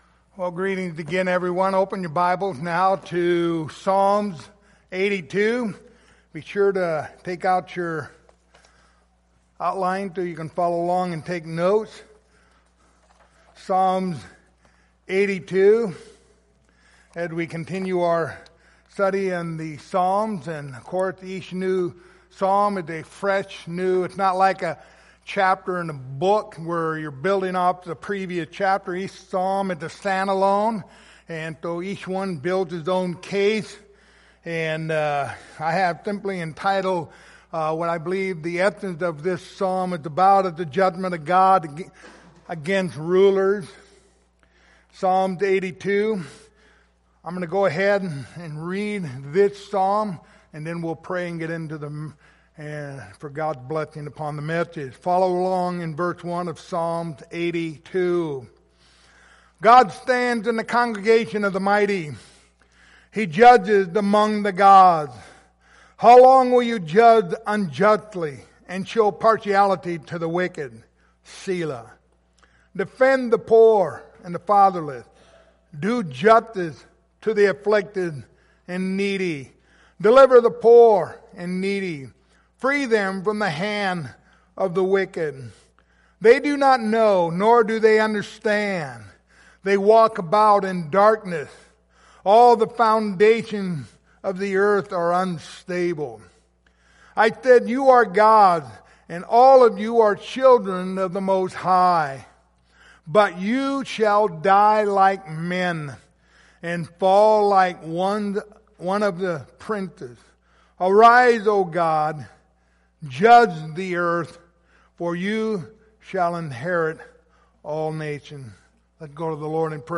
Passage: Psalms 82:1-8 Service Type: Sunday Morning